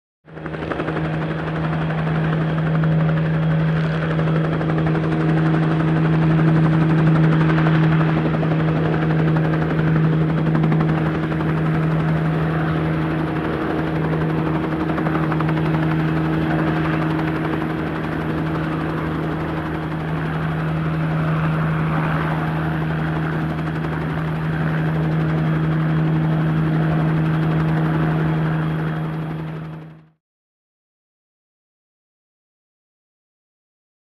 Helicopter Hover, Bell 47 Chopper, Exterior Perspective .